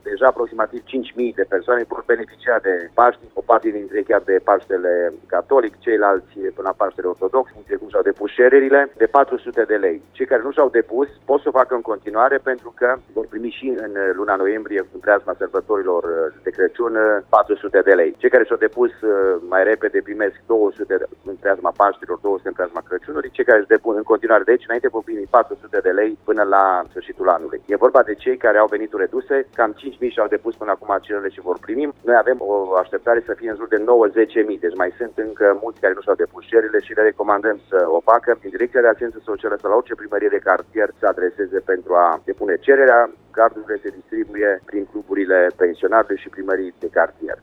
Primarul Emil Boc a precizat marți, 4 aprilie, la Radio Cluj, că o parte dintre cei care au solicitat deja acest sprijin pot primi înainte de Paști 200 de lei și alți 200 de lei înainte de Crăciun: